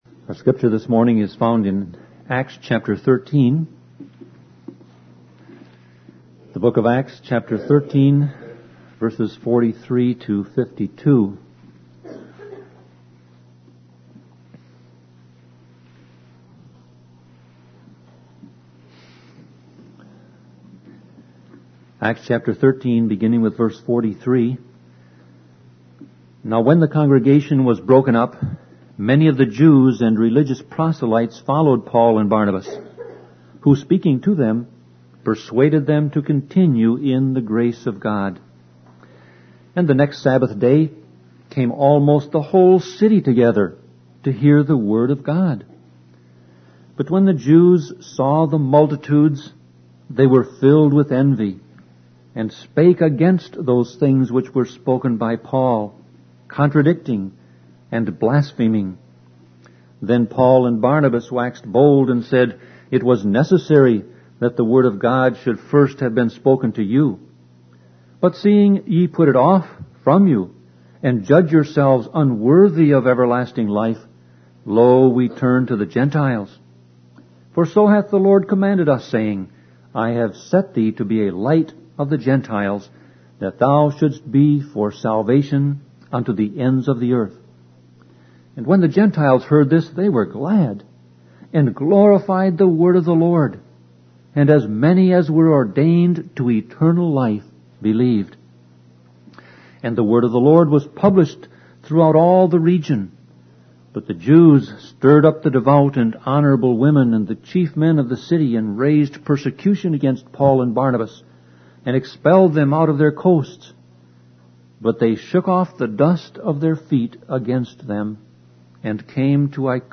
Sermon Audio Passage: Acts 13:43-52 Service Type